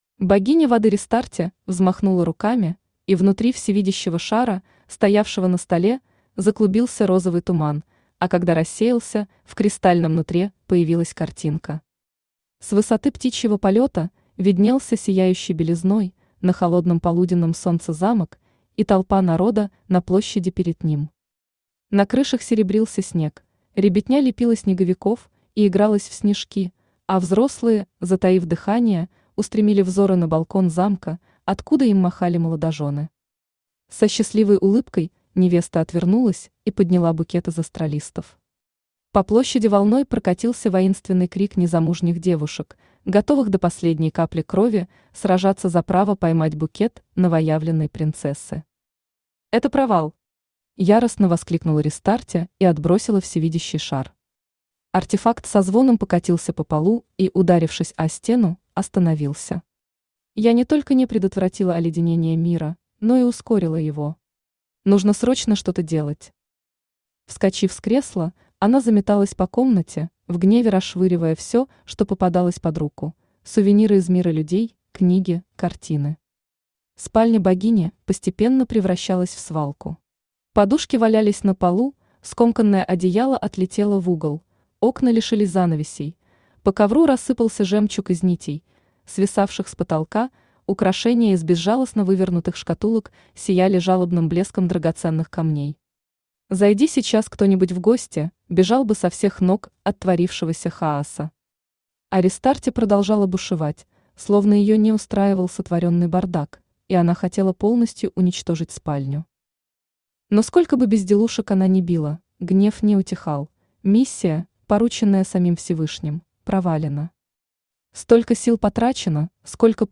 Аудиокнига Миссия попаданки: влюбить в себя Повелителя стужи | Библиотека аудиокниг
Aудиокнига Миссия попаданки: влюбить в себя Повелителя стужи Автор Анастасия Петровна Рогова Читает аудиокнигу Авточтец ЛитРес.